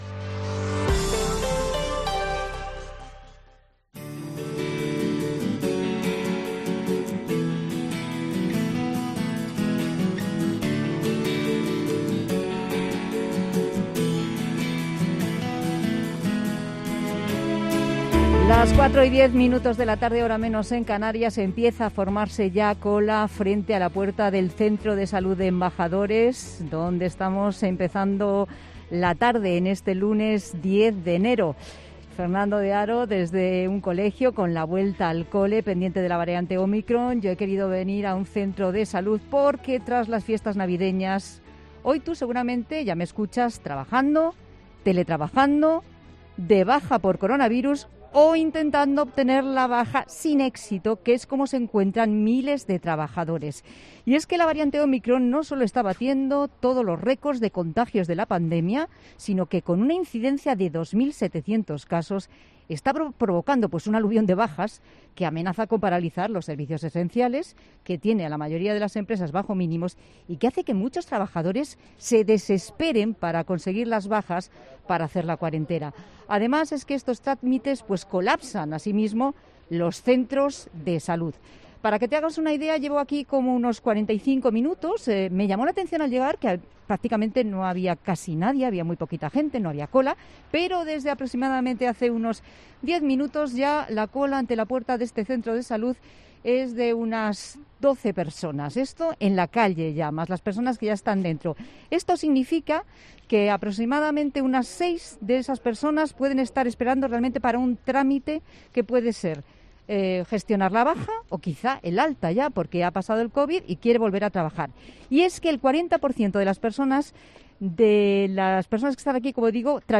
Además, estos trámites colapsan los centros de salud y es por eso que 'La Tarde COPE' ha salido hasta uno de ellos.